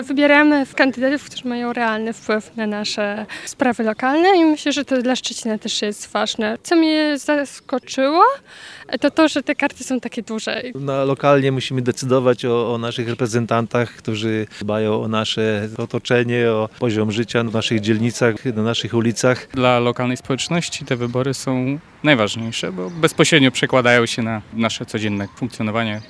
Dla lokalnej społeczności te wybory są najważniejsze, bo bezpośrednio przekładają się na nasze codzienne funkcjonowanie – mówią głosujący mieszkańcy Szczecina